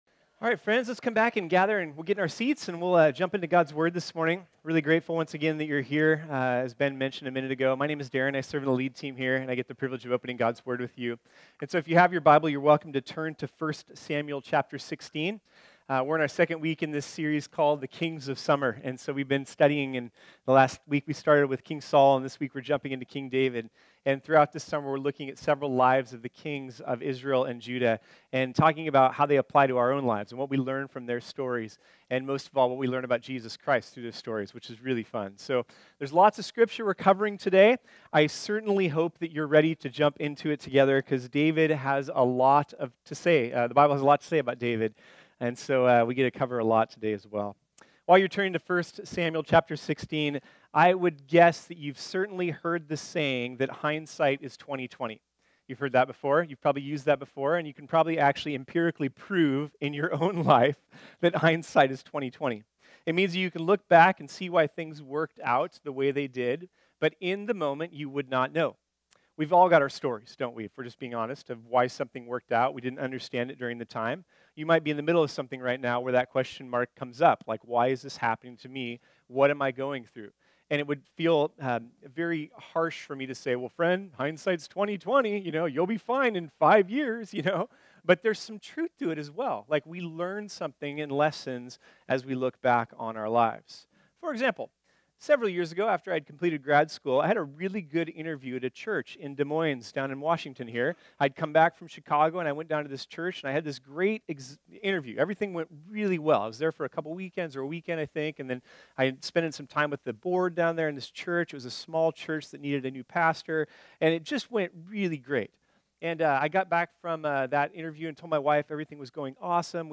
This sermon was originally preached on Sunday, June 10, 2018.